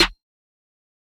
Toomp Snare 1.wav